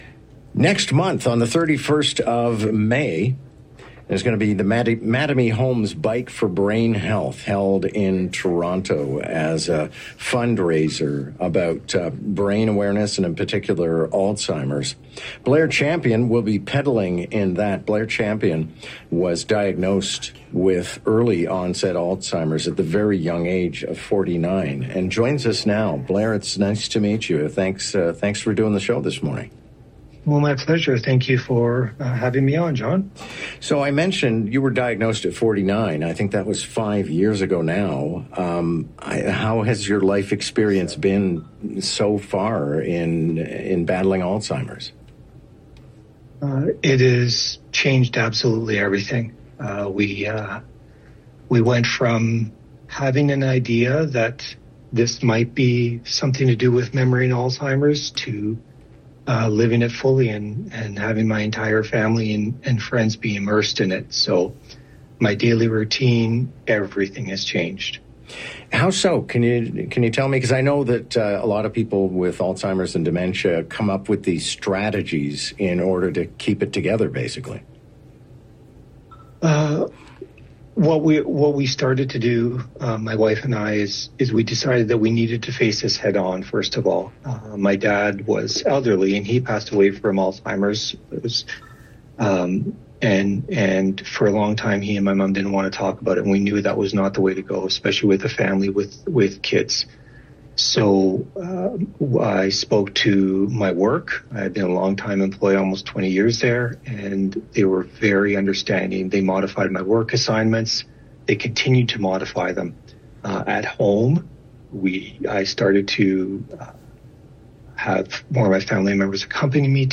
Listen to Newstalk 1010 Interview here: